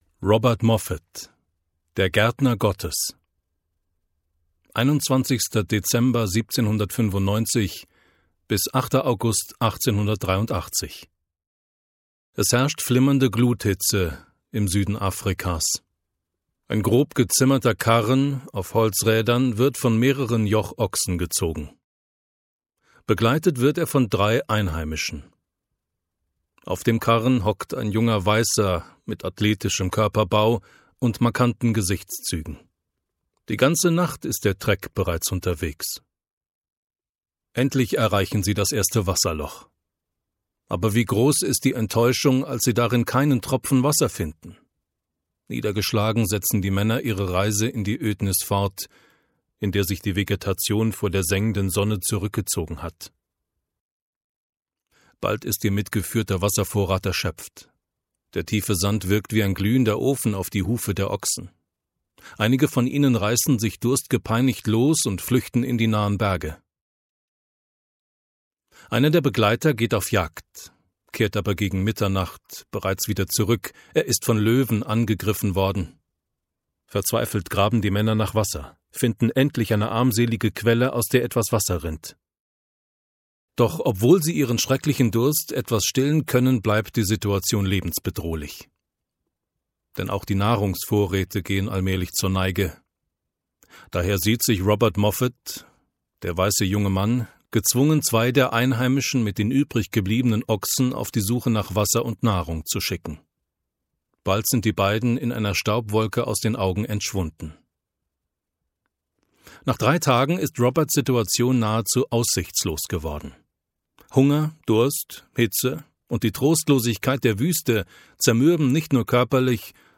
In diesem Hörbuch geht es um zehn Menschen, die Gott mit Treue und Hingabe als Missionare gedient haben.